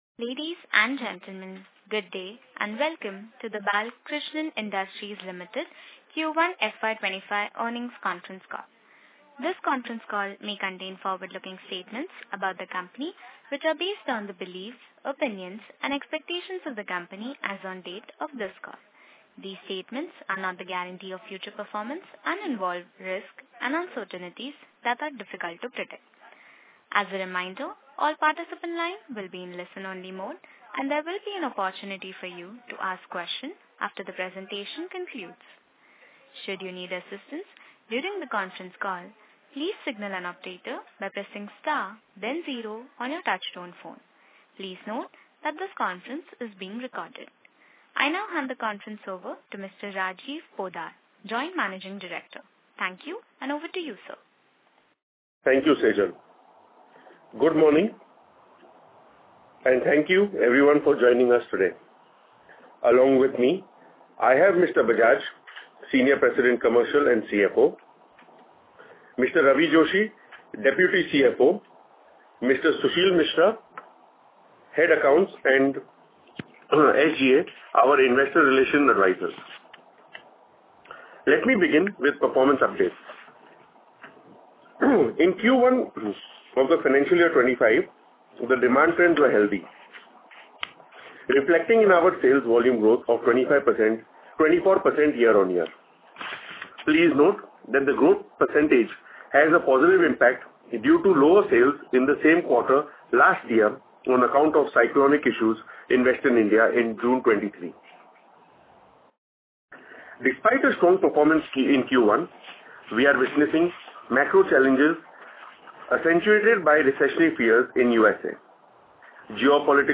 Audio recordings of conference Call dated August 10th, 2024